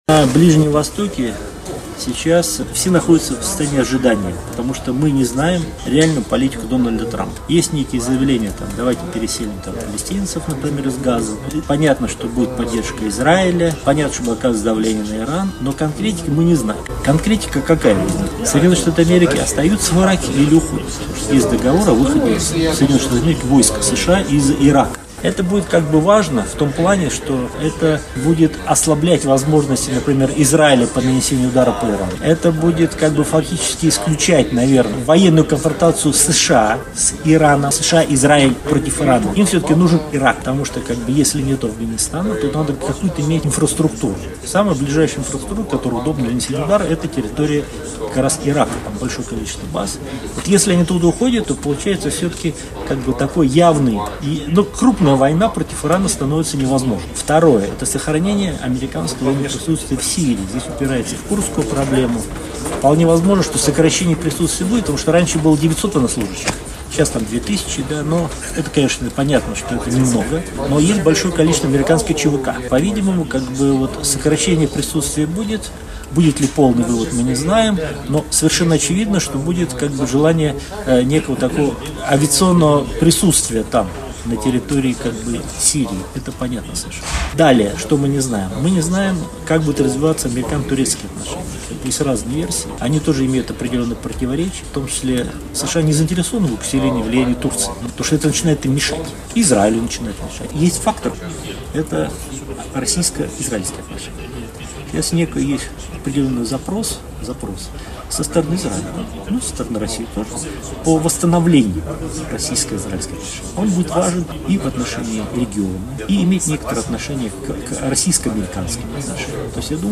ГЛАВНАЯ > Актуальное интервью
в интервью журналу «Международная жизнь» рассказал о ситуации на Ближнем Востоке: